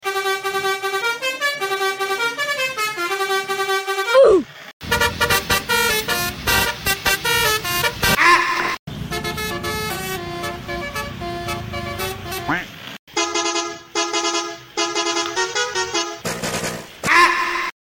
Mainan Anak, Kereta Api, Mobil sound effects free download
mobilan, excavator, truk